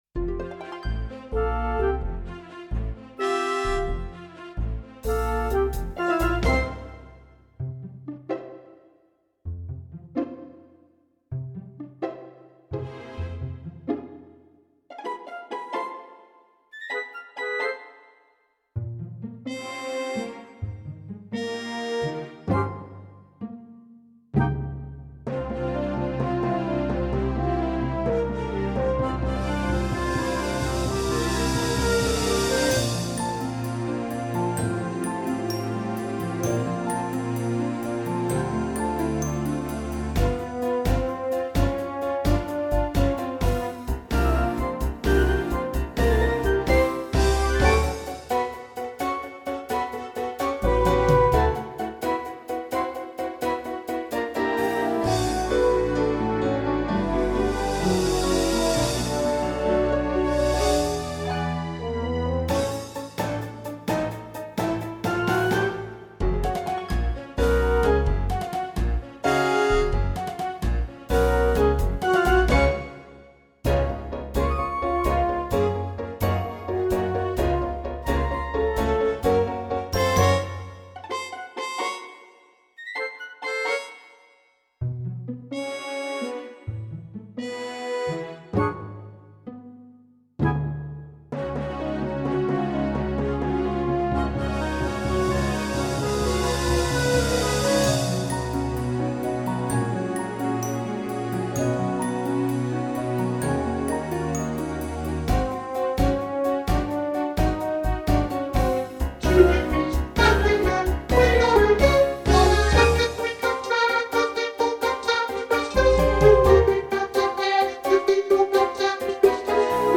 It-All-Comes-Down-To-Christmas-Alto | Ipswich Hospital Community Choir
It-All-Comes-Down-To-Christmas-Alto.mp3